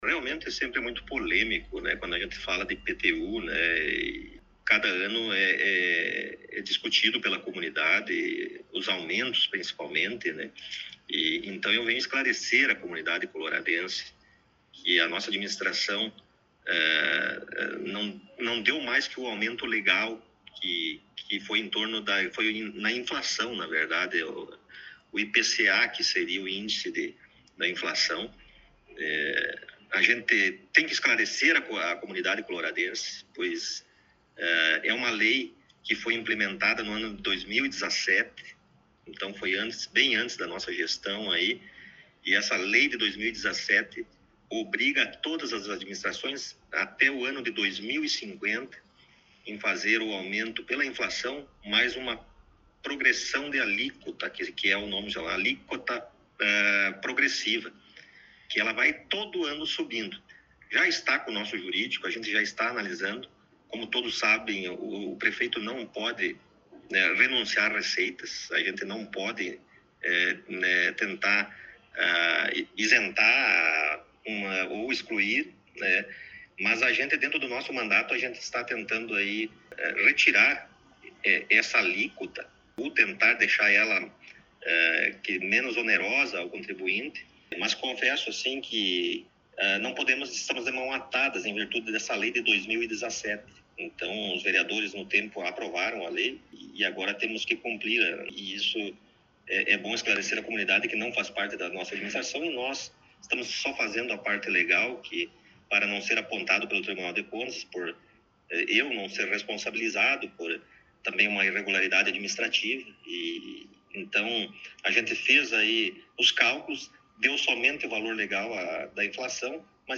Entrevista com o Prefeito Rodrigo Sartori: IPTU 2026 e Obras no Município
Na última semana, em um encontro no gabinete da prefeitura, tivemos a oportunidade de entrevistar o prefeito Rodrigo Sartori, logo após seu retorno das férias.